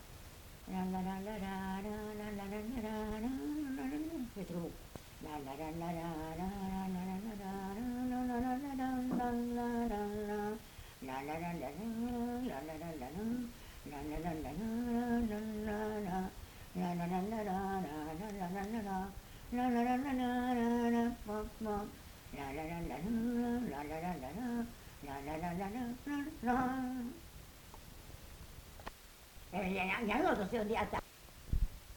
Lieu : Mas-Cabardès
Genre : chant
Effectif : 1
Type de voix : voix de femme
Production du son : fredonné
Danse : scottish